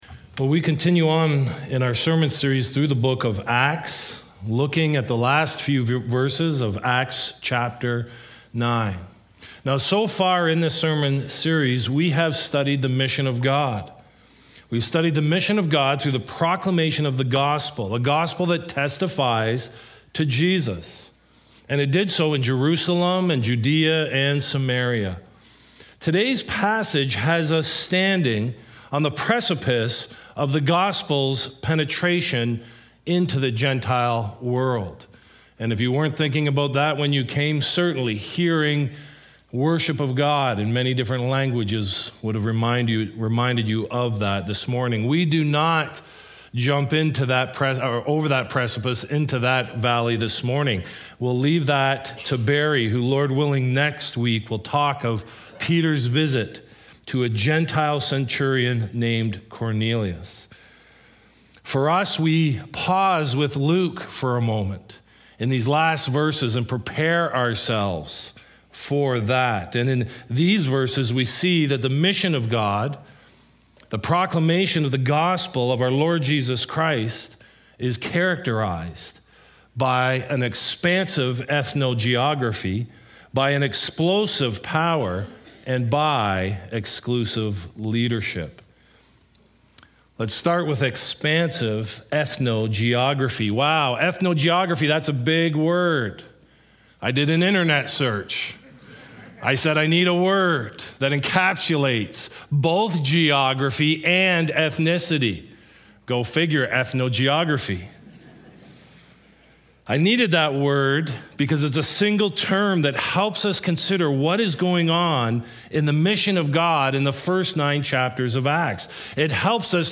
West London Alliance Church: Weekly Sermons
Weekly messages at West London Alliance Church